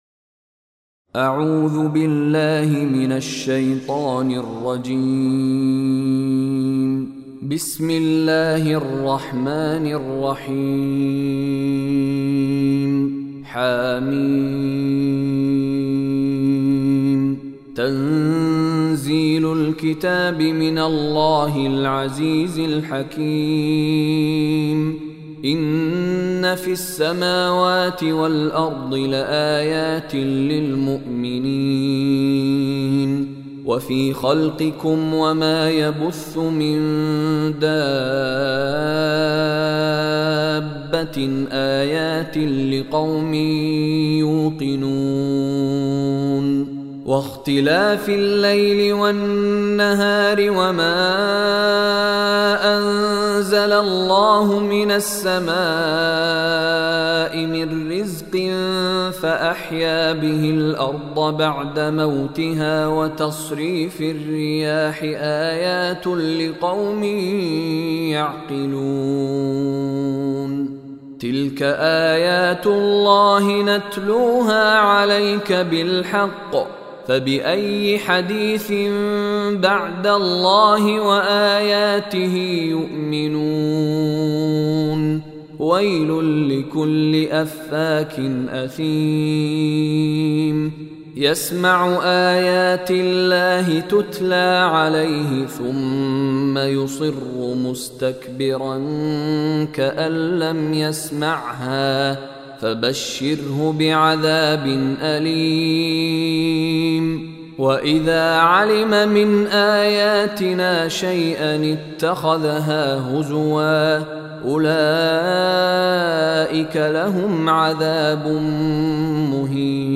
Surah Al Jathiya MP3 Recitation by Mishary Rashid
Surah Al-Jathiya is 45th chapter or Surah of Holy Quran. Listen online and download beautiful Quran tilawat / recitation of Surah Al-Jathiya in the voice of Sheikh Mishary Rashid Alafasy.